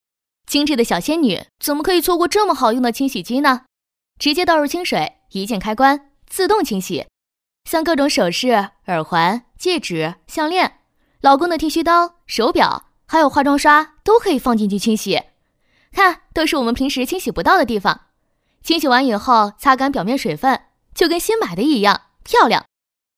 女285-抖音带货-【清洗机-自然口语】
女285-明亮柔和 素人自然